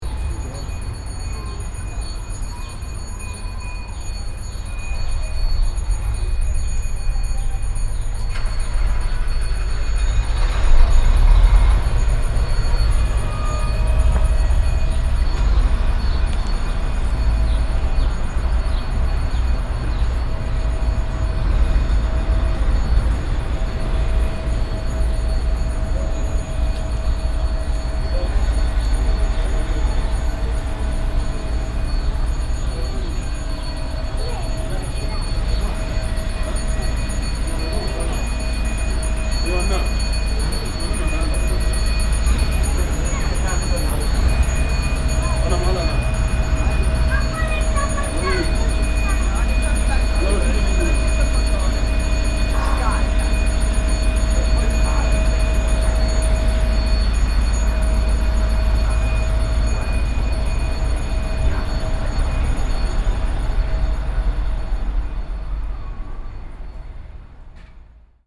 Rumore
Il campanello avvisa dell'arrivo di un treno in una stazione di provincia Ivrea, stazione ferroviaria
Microfoni binaurali stereo SOUNDMAN OKM II-K / Registratore ZOOM H4n